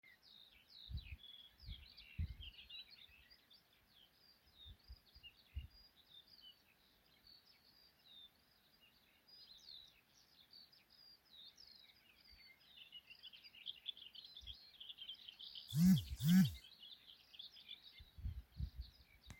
Booted Warbler, Iduna caligata
Administratīvā teritorijaLīvānu novads
StatusSinging male in breeding season